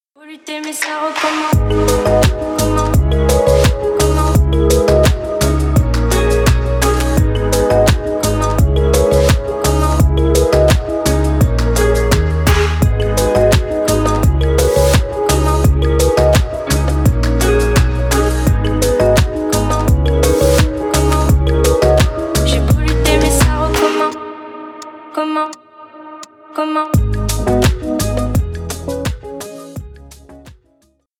Танцевальные
кавер